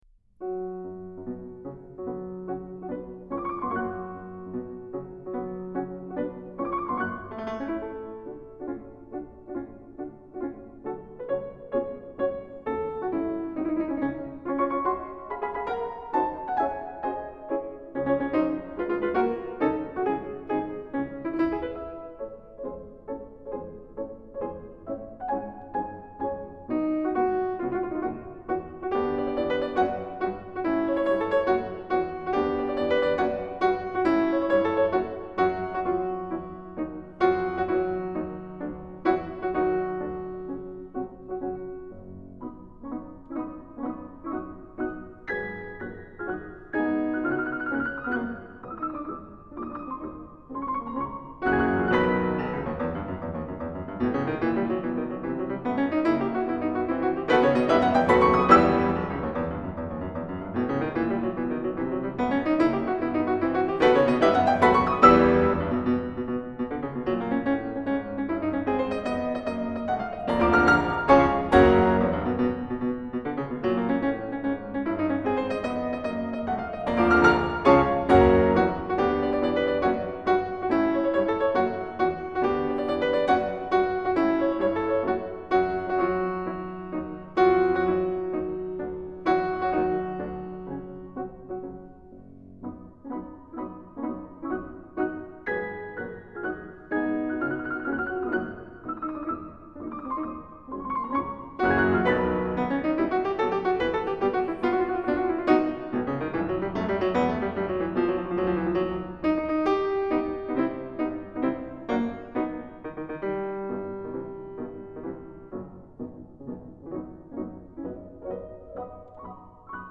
Juegos de Niños Op.22 (versión original para piano a cuatro manos)
Música clásica